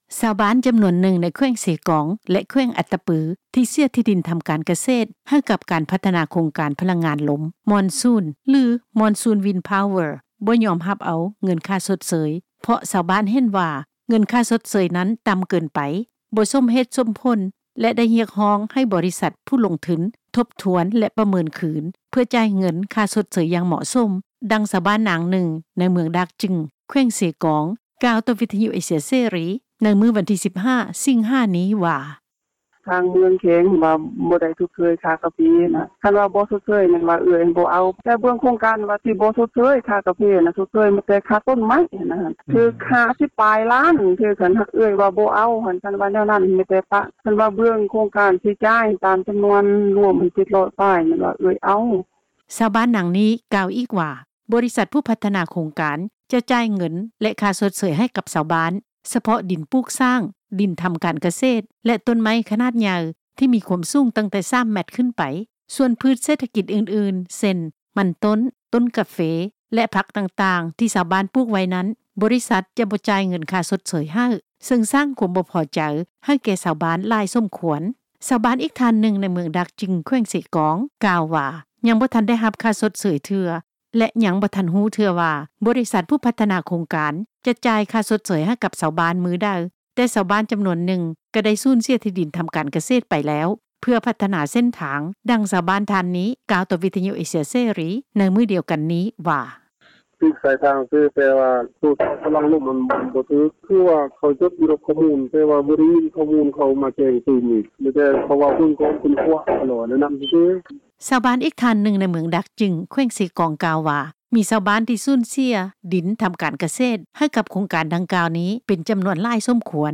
ຊາວບ້ານຈໍານວນນຶ່ງ ໃນແຂວງເຊກອງ ແລະ ແຂວງອັດຕະປື ທີ່ສູນເສັຍດິນ ທໍາການກະເສດ ໃຫ້ກັບການພັດທະນາ ໂຄງການພະລັງງານລົມ ມອນຊູນ ຫລື Monsoon wind Power ບໍ່ຍອມຮັບເອົາ ເງິນຄ່າຊົດເຊີຍ ເພາະຊາວບ້ານ ເຫັນວ່າ ເງິນຄ່າຊົດເຊີຍນັ້ນ ຕ່ໍາເກີນໄປ ບໍ່ສົມເຫດສົມຜົນ ແລະ ຮຽກຮ້ອງ ໃຫ້ບໍຣິສັດຜູ້ລົງທຶນ ທົບທວນ ແລະ ປະເມີນຄືນ ເພື່ອຈ່າຍເງິນ ຄ່າຊົດເຊີຍ ຢ່າງເໝາະສົມ. ດັ່ງ ຊາວບ້ານນາງນຶ່ງ ໃນເມືອງດາກຈຶງ ແຂວງເຊກອງ ກ່າວຕໍ່ວິທຍຸ ເອເຊັຍເສຣີ ໃນມື້ວັນທີ 15 ສິງຫາ ນີ້ວ່າ:
ທາງດ້ານເຈົ້າໜ້າທີ່ ຜະແນກພະລັງງານ ແລະ ບໍ່ແຮ່ ແຂວງເຊກອງ ກ່າວວ່າ ປັດຈຸບັນ ມີຊາວບ້ານ ຈໍານວນນຶ່ງ ເກີດຄວາມບໍ່ພໍໃຈ ຕໍ່ກັບ ໂຄງການພັດທະນາ ດັ່ງກ່າວ ສາເຫດມາຈາກ ຊາວບ້ານ ບໍ່ໄດ້ຮັບຄວາມຍຸຕິທັມ ເລື້ອງການຈ່າຍເງິນຄ່າຊົດເຊີຍ ທີ່ມີມູນຄ່າຕ່ໍາເກີນໄປ ແລະ ຊັບສິນ ປະເພດ ພືດເສດຖະກິດຕ່າງໆ ກະບໍ່ໄດ້ມີການປະເມີນລາຄາ ເນື່ອງຈາກບໍລິສັດ ກະຕັ້ງໃຈວ່າ ຈະບໍ່ຈ່າຍ ເງິນຄ່າຊົດເຊີຍ ໃນສ່ວນນີ້ ເຊິ່ງປັດຈຸບັນ ກໍາລັງຢູ່ລະຫວ່າງ ການໄກ່ເກັ່ຍກັນຢູ່ ຍັງບໍ່ທັນໄດ້ຂໍ້ສະຫລຸບເທື່ອ. ດັ່ງ ເຈົ້າໜ້າທີ່ທ່ານນີ້ ກ່າວຕໍ່ ວິທຍຸ ເອເຊັຍເສຣີ ໃນມື້ດຽວກັນນີ້ວ່າ: